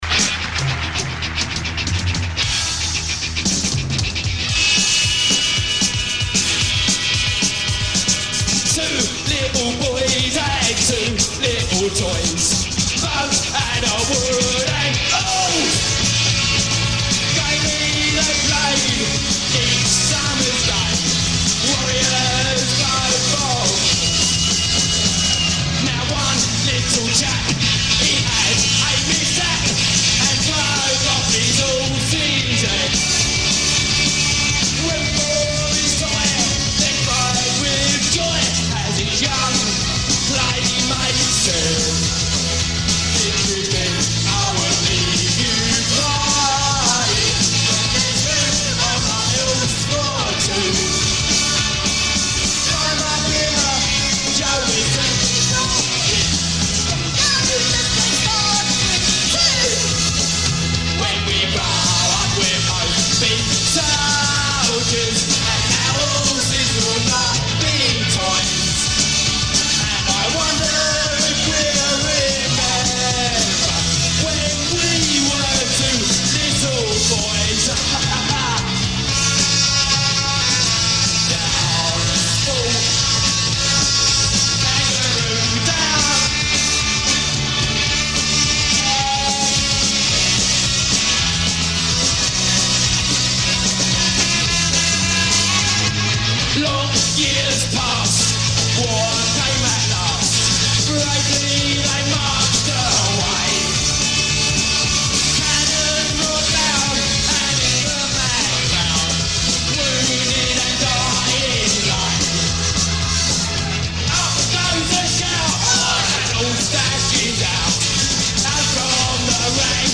taken from VHS